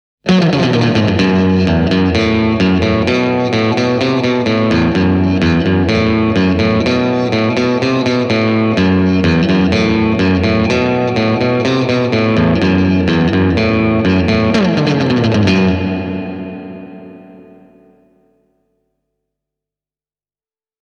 AC30S1:n digitaalisesti mallinnettu jousikaiku kuulostaa erinomaiselta – tämä on ehkä paras putkivahvistimen sisäinen digikaiku pitkään aikaan: